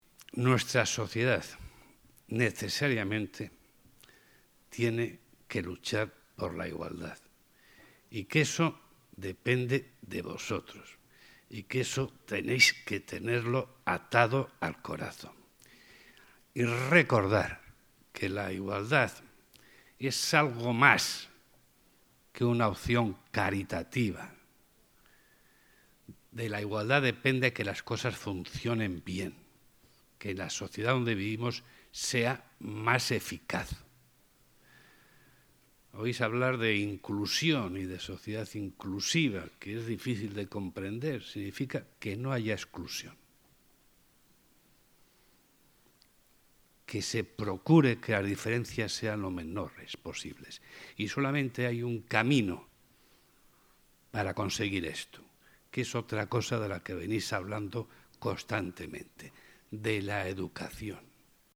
afirmó el presidente del CES (archivo MP3), y recordó a los escolares que "la dignidad de una sociedad se mide por la fortaleza del eslabón más débil, de los que más difícil lo tienen".